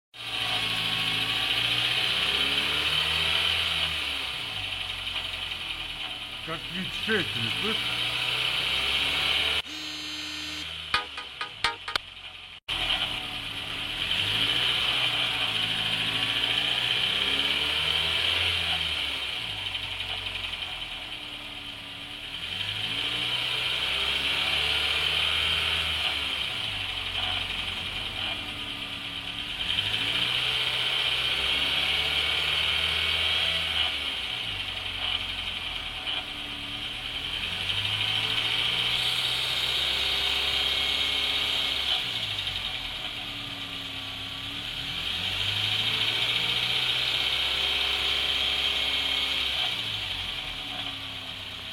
Пример шума вариантора или раздатки:
Шум вариатора или раздатки
shum-variatora-ili-razdatki.mp3